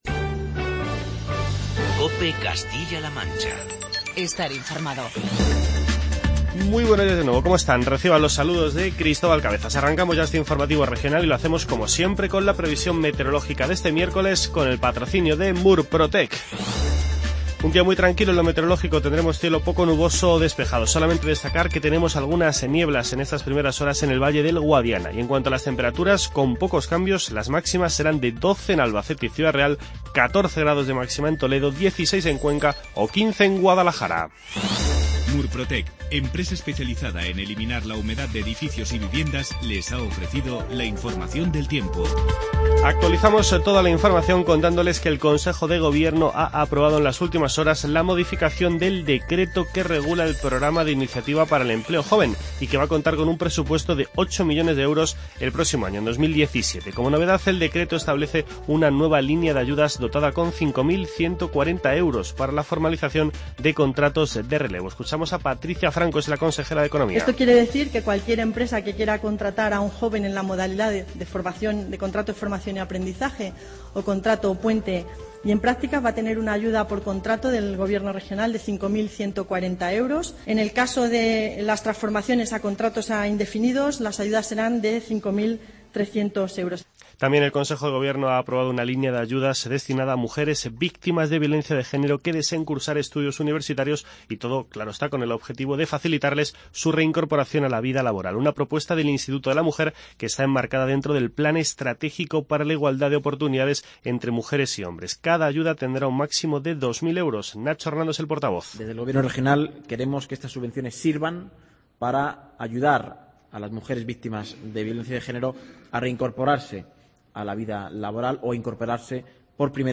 Informativo regional y provincial